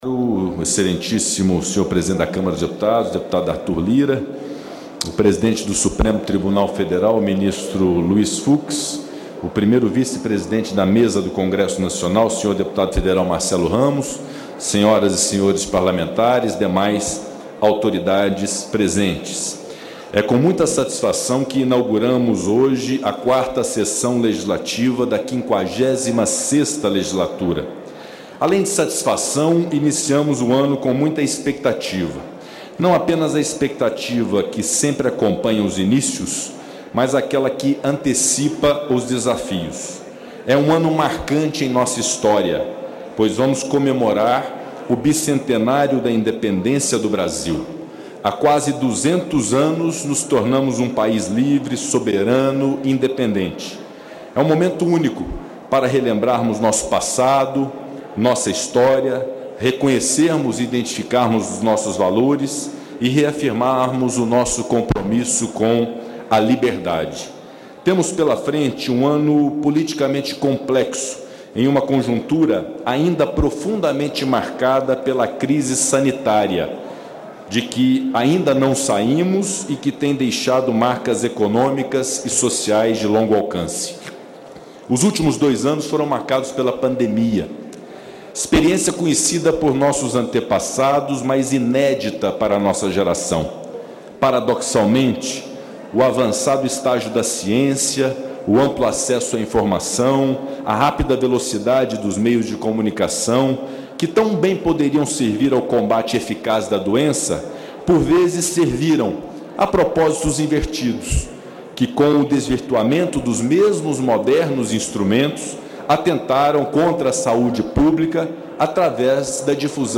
Em pronunciamento na abertura dos trabalhos legislativos deste ano, o presidente do Congresso, Rodrigo Pacheco, disse que a defesa da democracia em ano eleitoral é um dos desafios do Brasil em 2022. Pacheco fez também um balanço da contribuição do legislativo para o enfrentamento da crise gerada pela pandemia e para a retomada do crescimento econômico. Ouça a íntegra do pronunciamento.